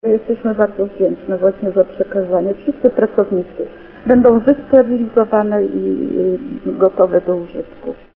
Dzisiaj (26.03) zadzwoniła do Radia 5 jedna z osób pracujących w przychodni przy ulicy Grodzieńskiej w Ełku.